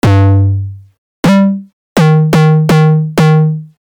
Step 4: Distortion
Now that we have created the tonal and pitch bending 808 kick we need to add some distortion to get that bite you hear in the tune.